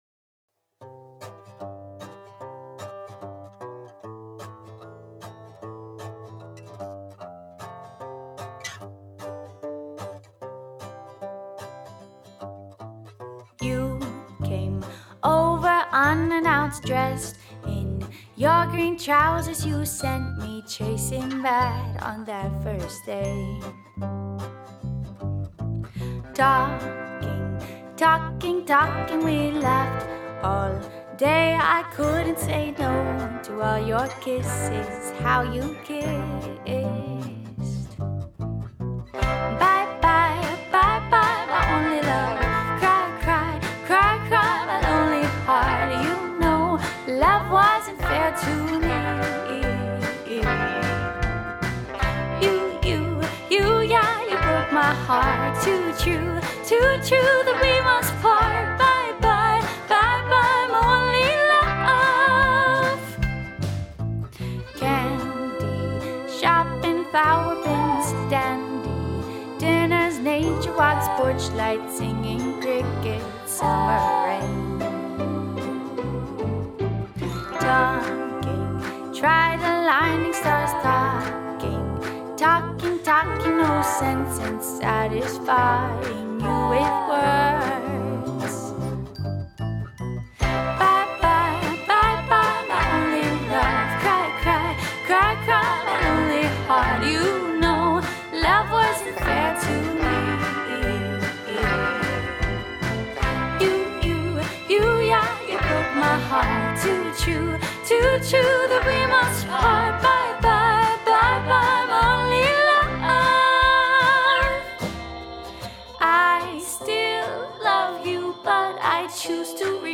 It basically follows a VERSE CHORUS VERSE CHORUS BRIDGE CHORUS pattern with each verse and chorus having six lines.
The melody of this composition is quite old school and does not break a lot of new ground. That being said, it has a cutesy, kitschy quality to it and could be produced in a very kittenish retro fashion. Some of the vocalist’s stylings, such as singing single syllable line-end words in a broken two syllable way, suggest to me that a retro feel was what the writer was going for.